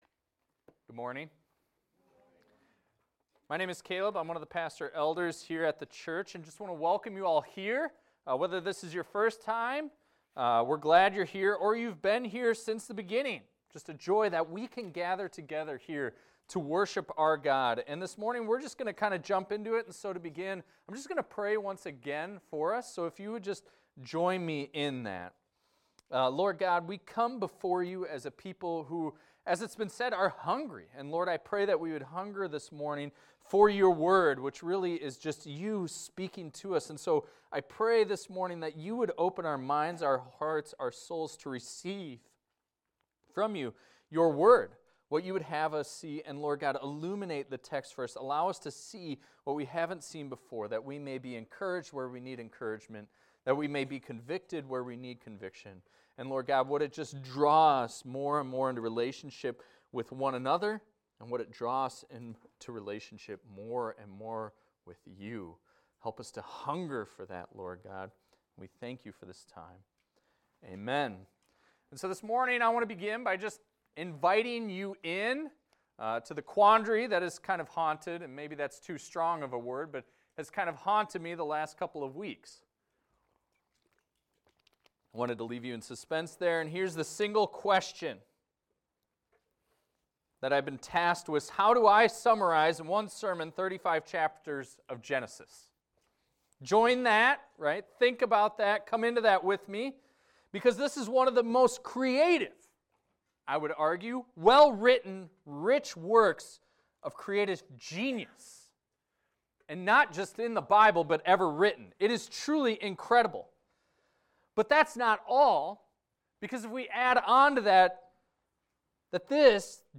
This is a recording of a sermon titled, "Genesis 1-35 Review."